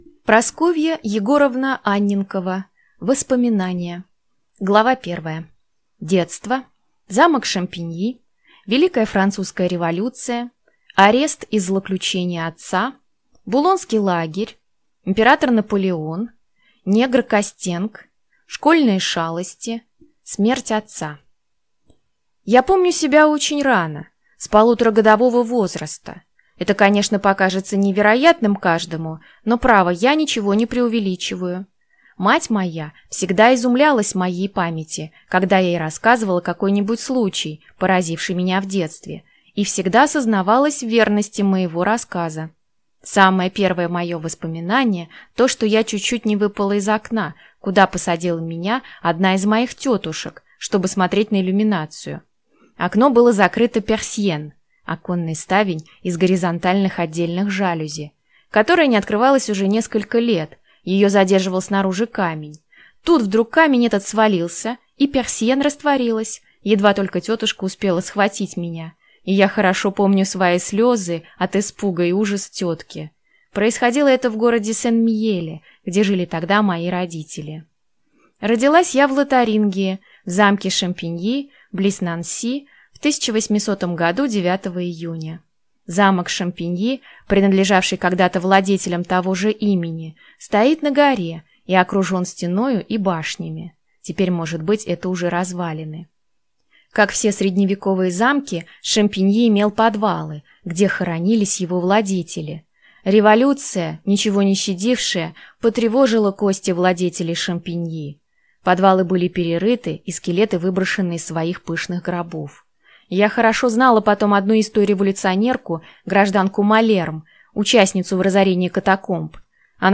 Аудиокнига Воспоминания | Библиотека аудиокниг